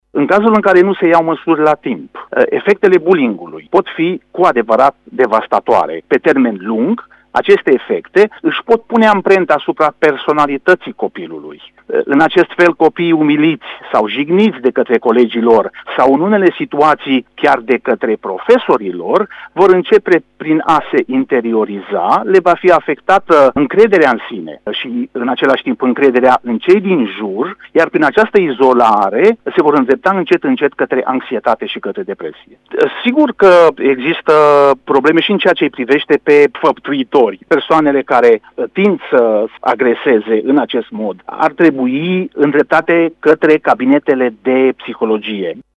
doctor în spihologie: